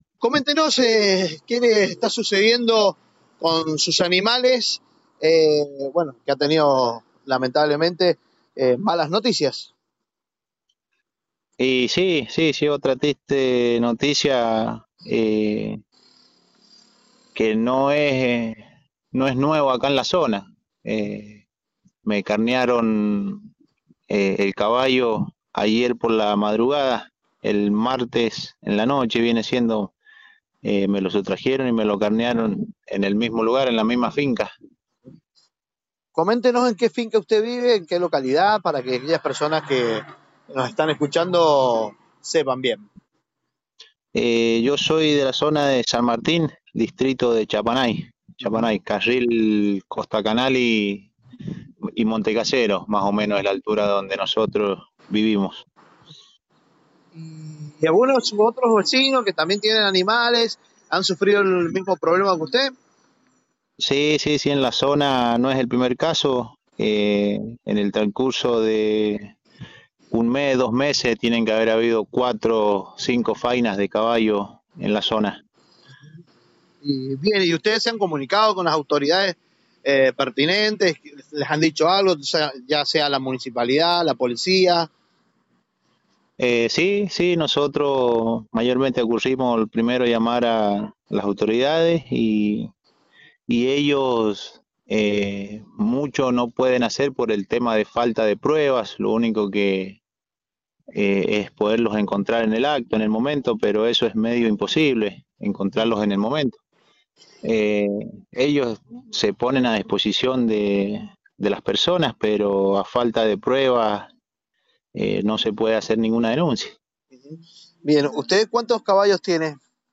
A continuación les dejamos el audio completo de la entrevista